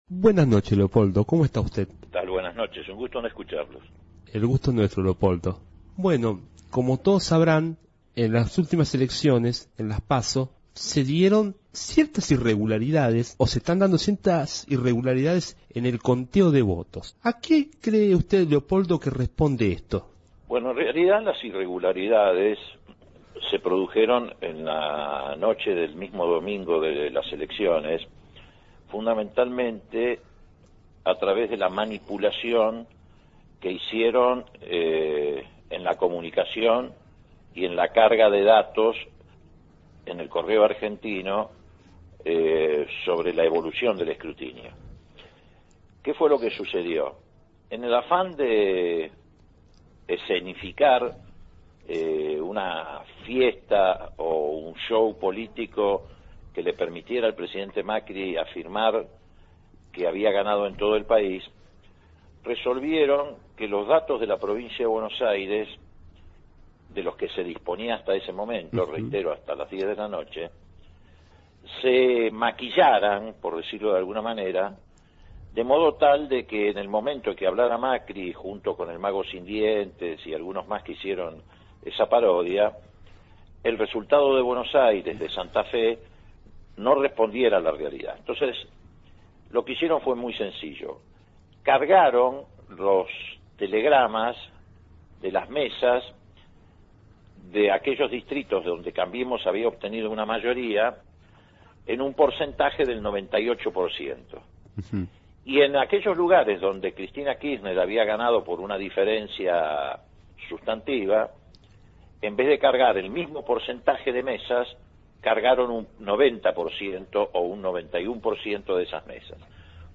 El candidato a diputado nacional por Unidad Ciudadana Leopoldo Moreau habló en el programa «universos paralelos»(Sábados 19hs-Radio verdad fm99.5) y realizó duras declaraciones sobre las irregularidades en las PASO.